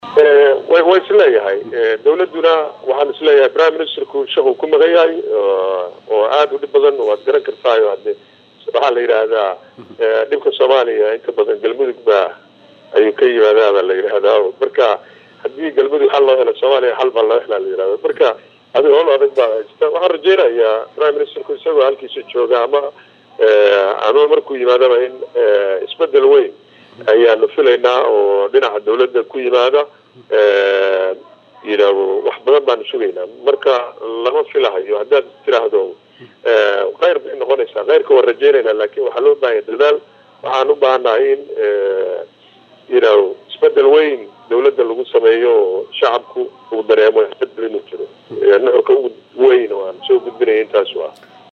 Xildhibaanka oo wareysi siiyey Idaacadda Risaala ee magaalada Muqdisho ayaa shaaciyey in dhibka Soomaaliya uu ka yimaado Galmudug, islamarkaana ay muhiim tahay in xal laga gaaro khilaafka la dagay maamulkaasi.